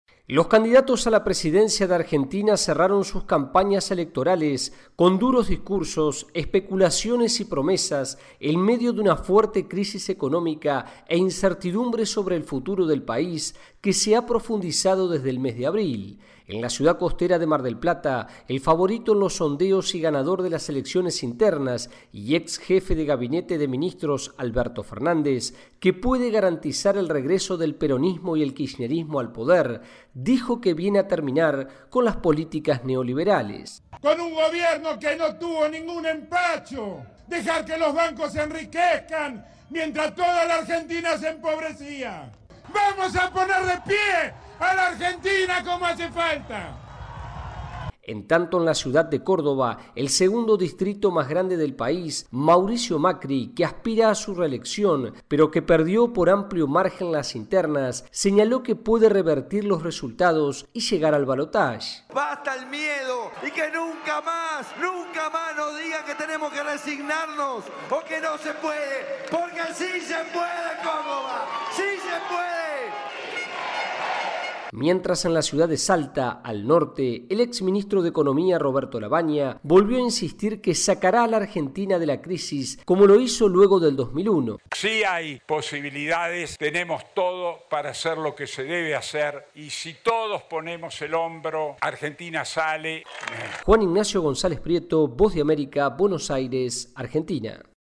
VOA: Informe de Argentina